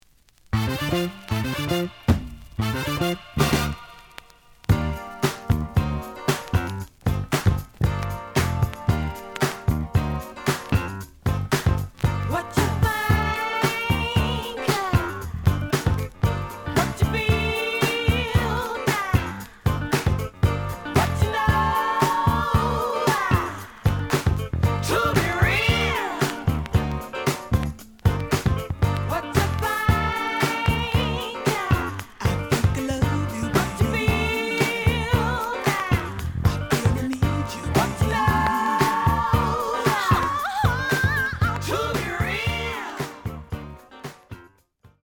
The audio sample is recorded from the actual item.
●Genre: Disco
Some click noise on both sides.)